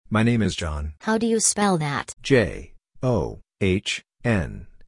Example Dialogue: